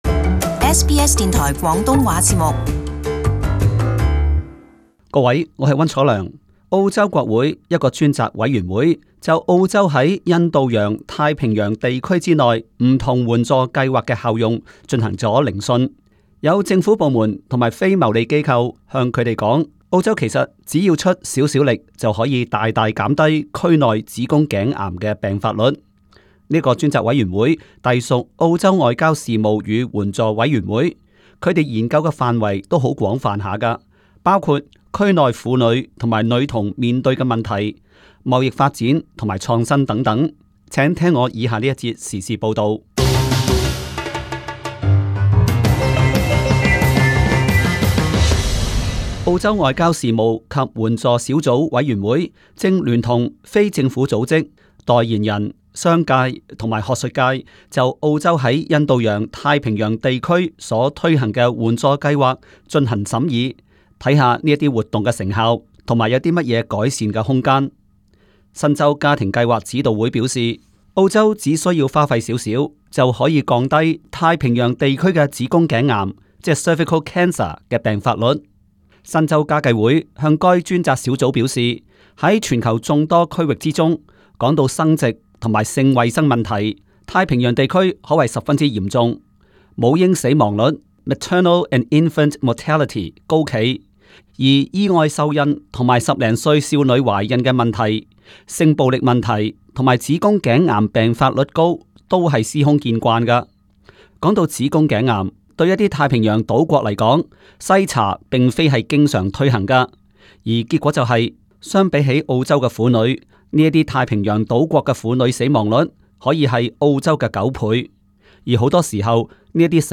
【时事报导】国会正审议澳洲海外援助的成效